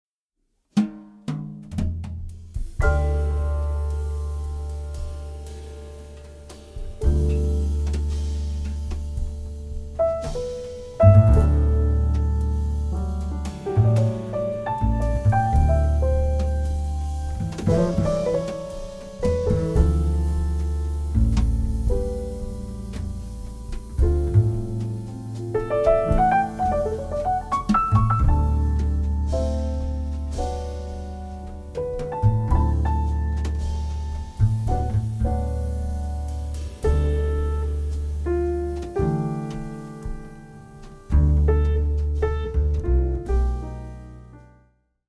クールで情熱的なオリジナルアレンジがジャズの伝統と現代を融合。
深いグルーヴ、洗練された旋律、躍動感が織りなす極上のサウンド。